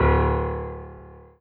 piano-ff-06.wav